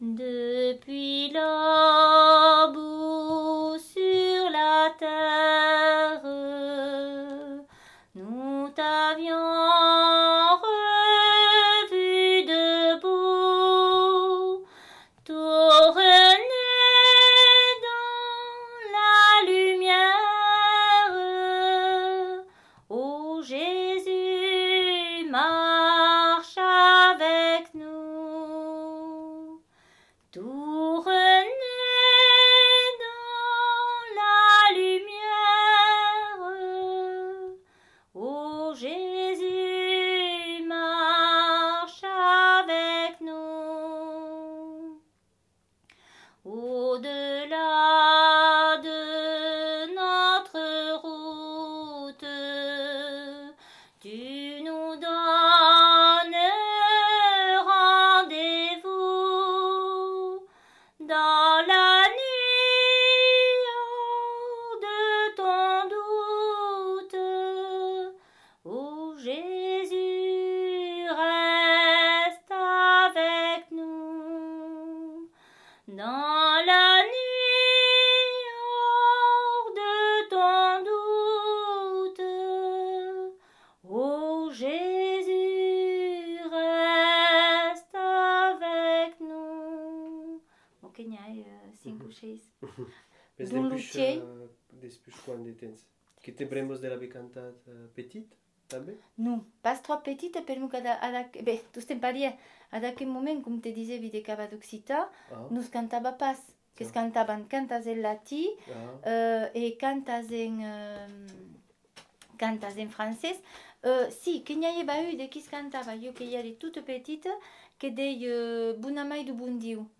Aire culturelle : Bigorre
Lieu : Ayros-Arbouix
Genre : chant
Effectif : 1
Type de voix : voix de femme
Production du son : chanté
Classification : cantique